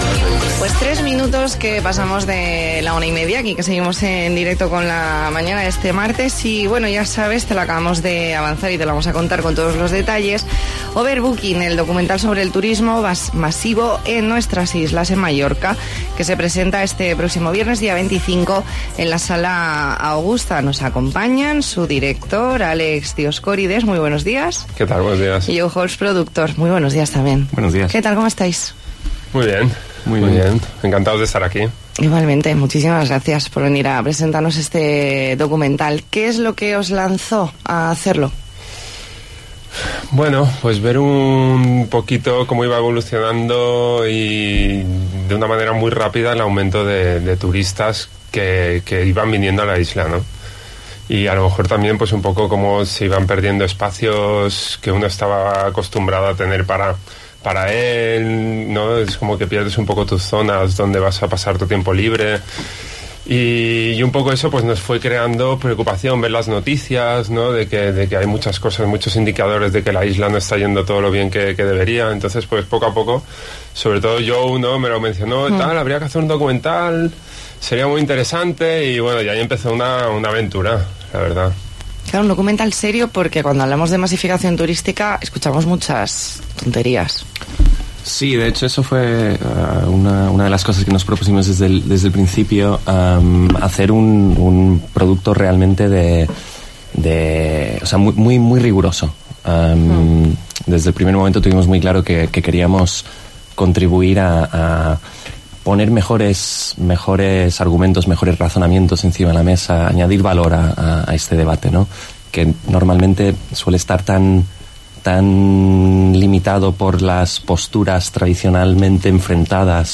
Entrevista en 'La Mañana en COPE Más Mallorca', martes 22 de enero de 2019.